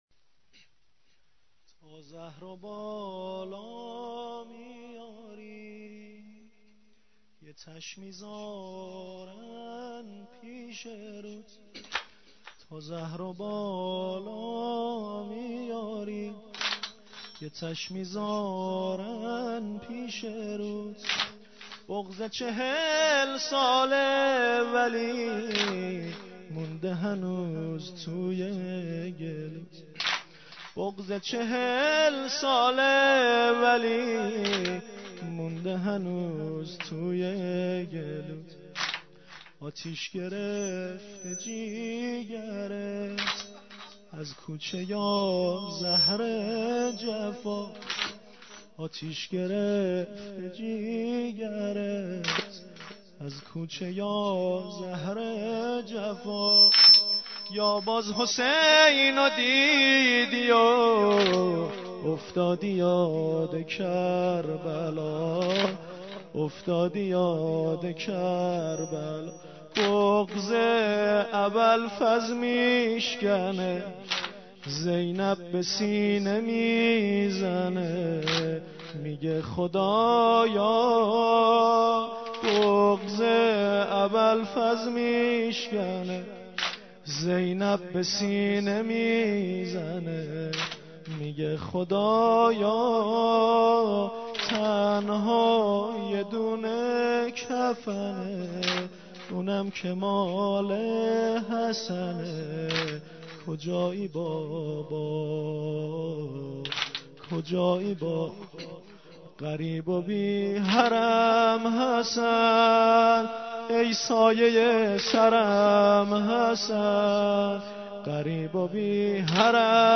مراسم هفتگی؛ 26 صفر 1434؛ روضه حضرت سیدالشهداء و امام حسن مجتبی علیهما السلام؛ قسمت ششم